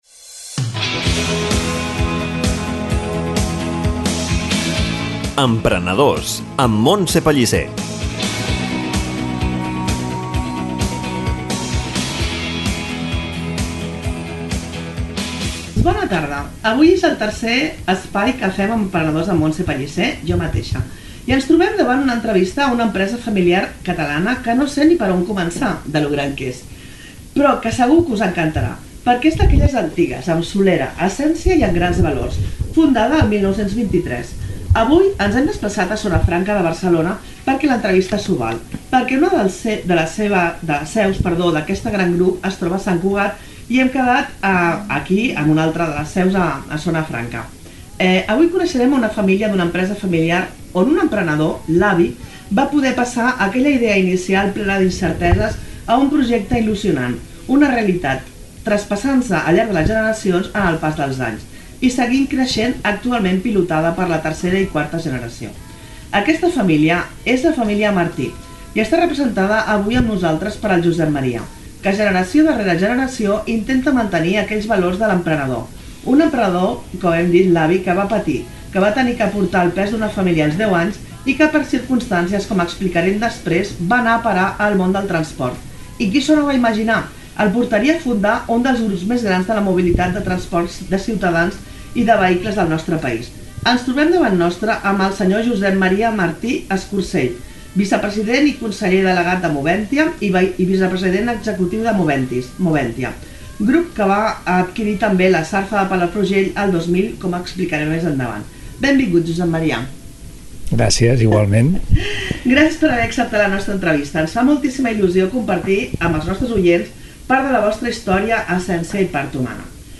Un gran plaer portar-vos aquesta tant entranyable entrevista que, a la ràdio, es farà amb dues parts en haver tantes coses interessants per compartir i explicar; espero que us agradi tant com a mi, tant agraïda d’haver-los pogut conèixer!